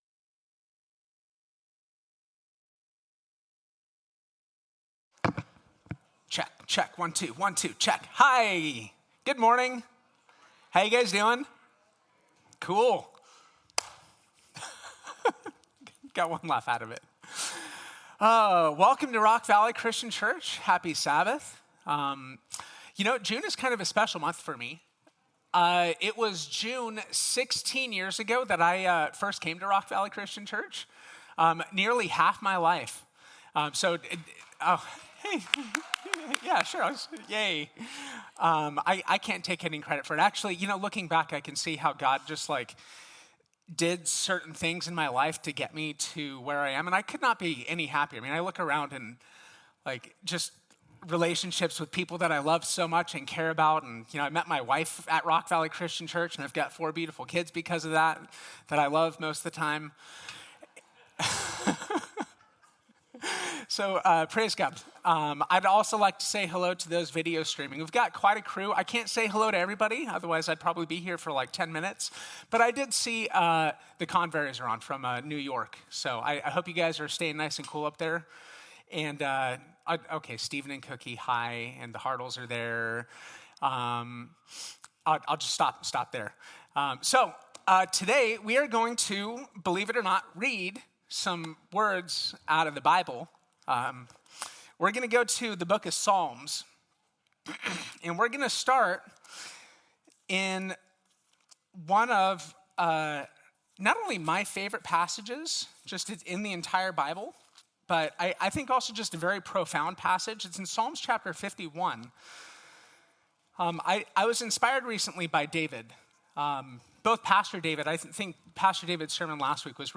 Clean Heart Sermon